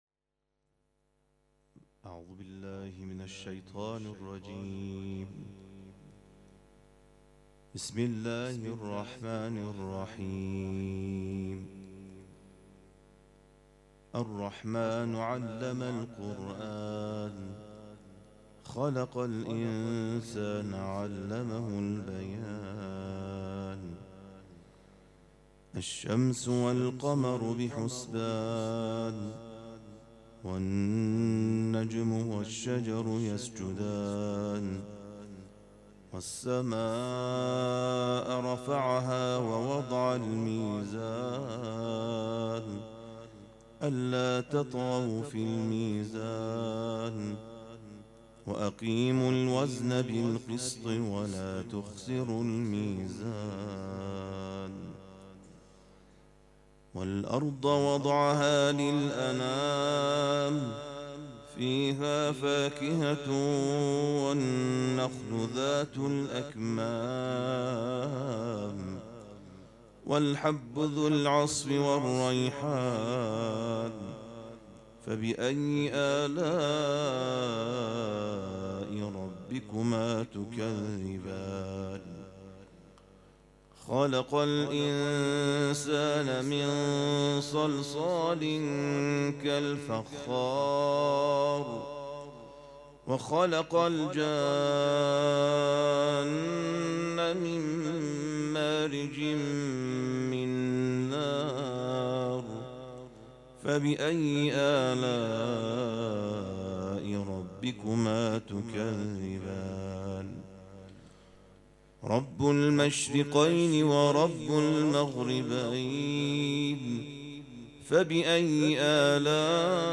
قرائت قرآن کریم